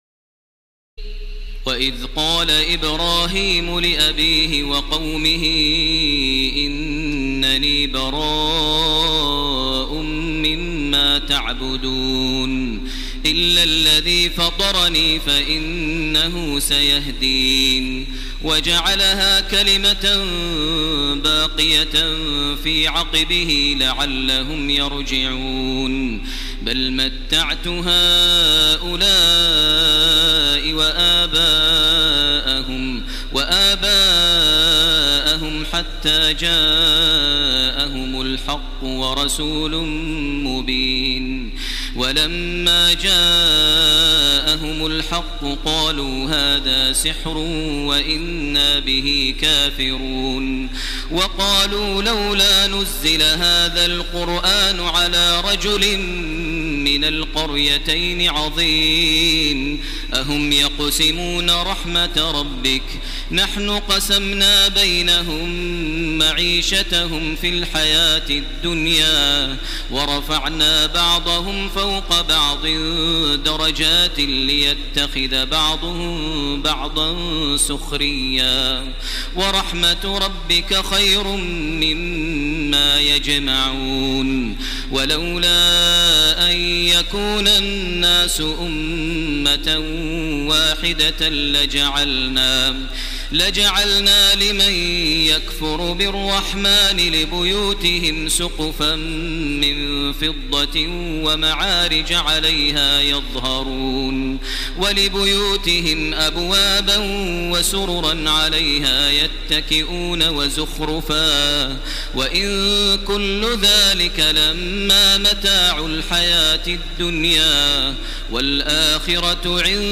تراويح ليلة 24 رمضان 1430هـ من سور الزخرف (26-89) والدخان و الجاثية Taraweeh 24 st night Ramadan 1430H from Surah Az-Zukhruf and Ad-Dukhaan and Al-Jaathiya > تراويح الحرم المكي عام 1430 🕋 > التراويح - تلاوات الحرمين